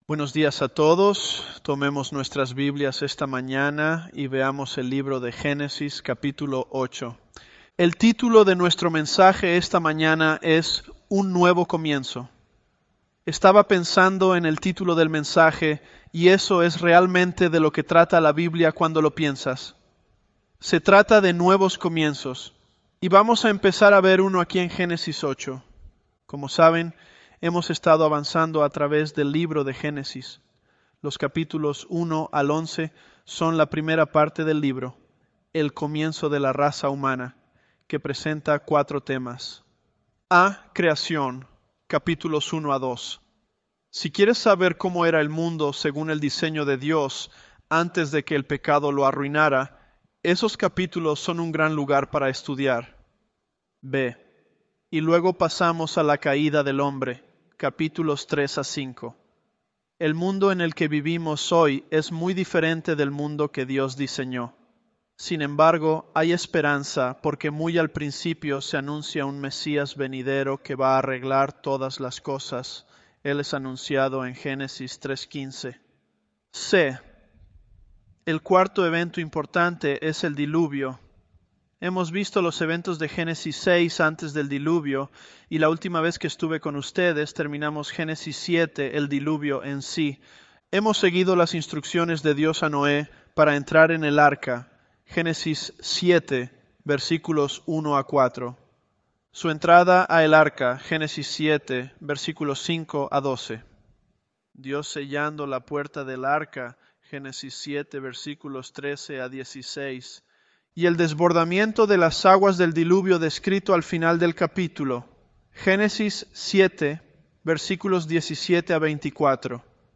ElevenLabs_Genesis-Spanish032.mp3